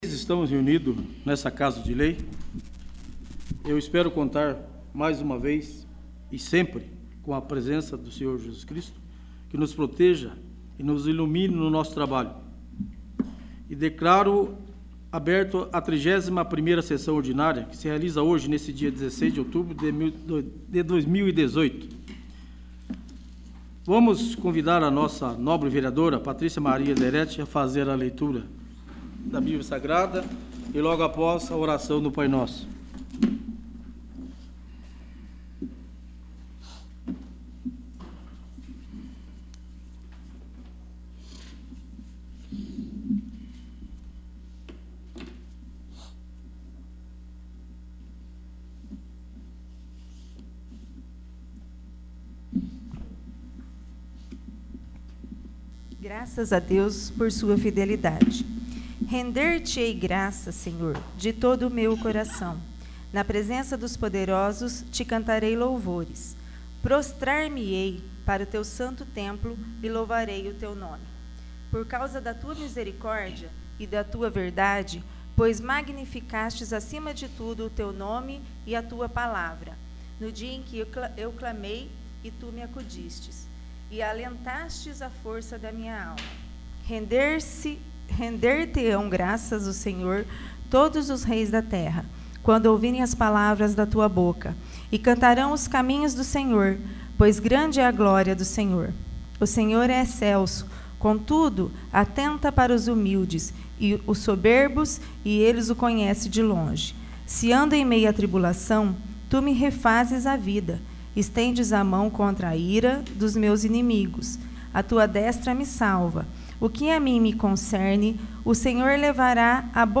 31º. Sessão Ordinária